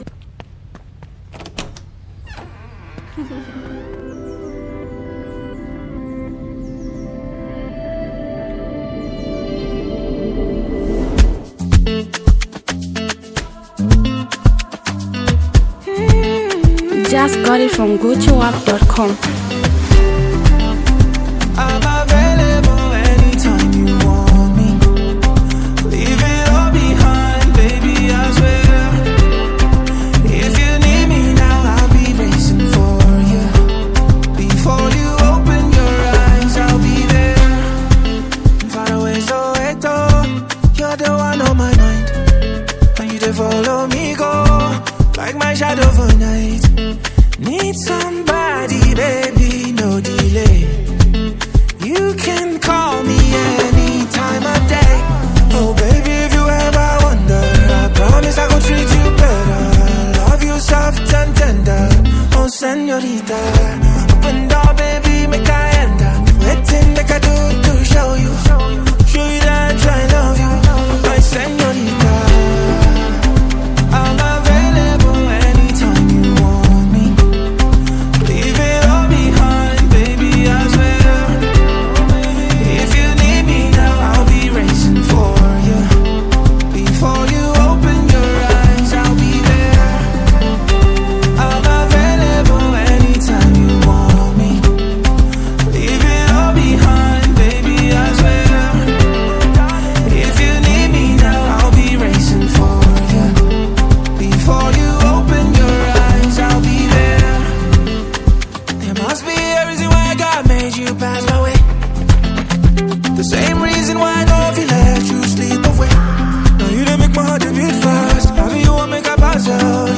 powerful melodic sound